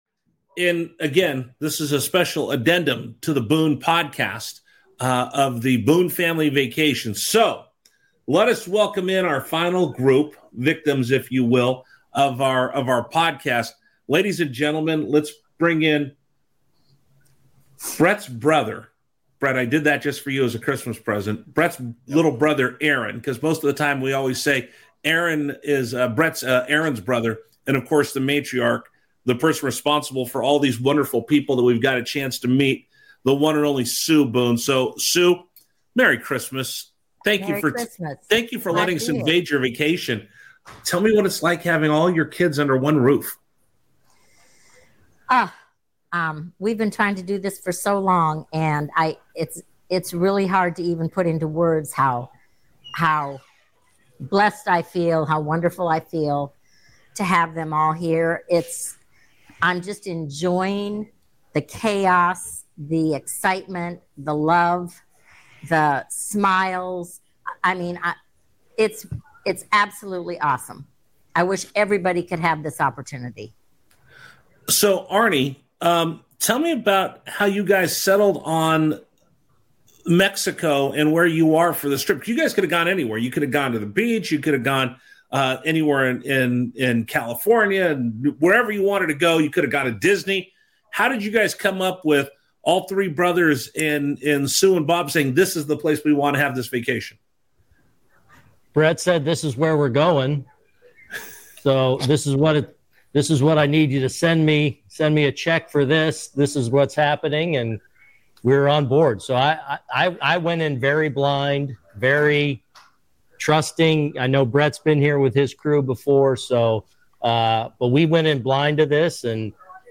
Boomer Esiason and Gregg Giannotti talk sports and interview the hottest names in sports and entertainment. Listen Live on WFAN.